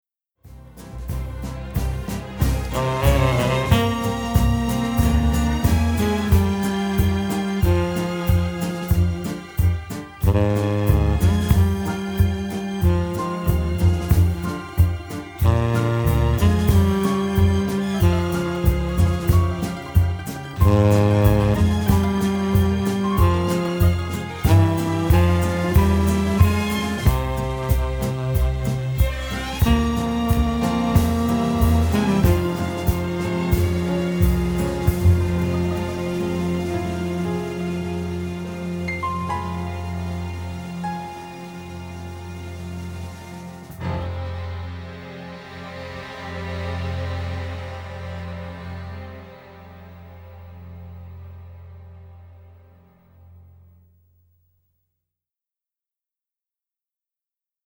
Recorded in Germany.